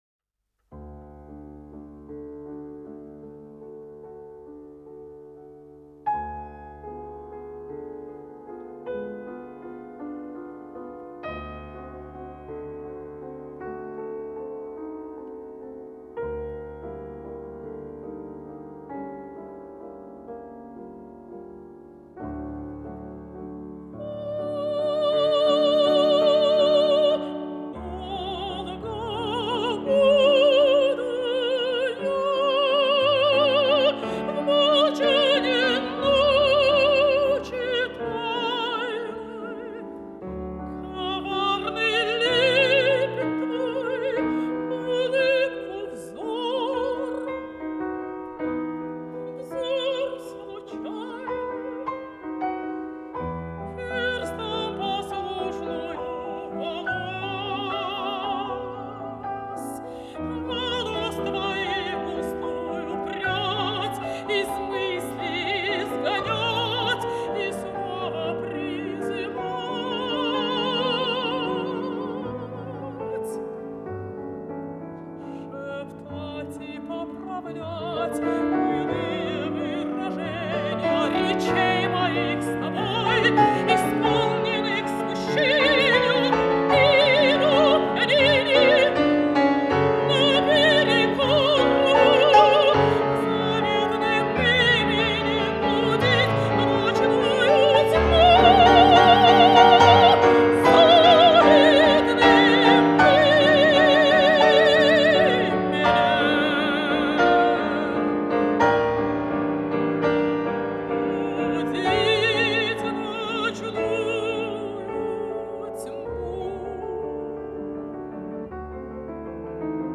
(фп.) - В молчаньи ночи тайной (С.Рахманинов - А.Фет) (25.08.1970)